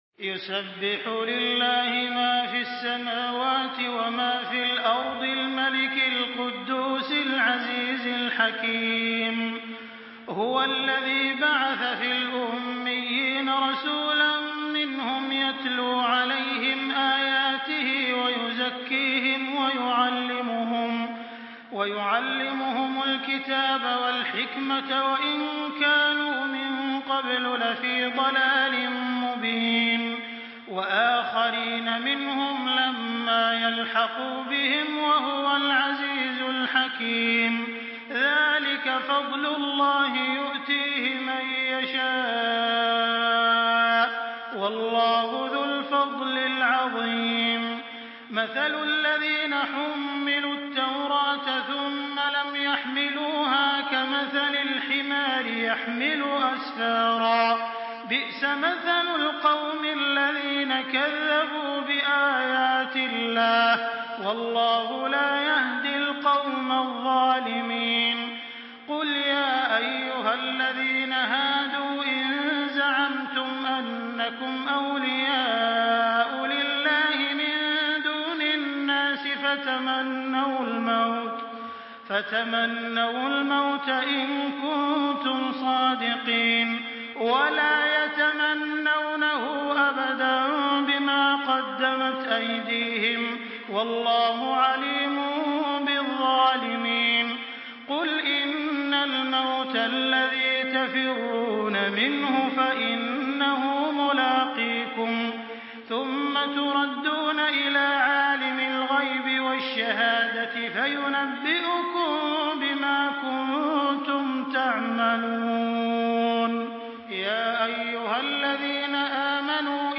دانلود سوره الجمعه توسط تراويح الحرم المكي 1424